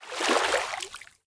latest / assets / minecraft / sounds / mob / dolphin / jump1.ogg
jump1.ogg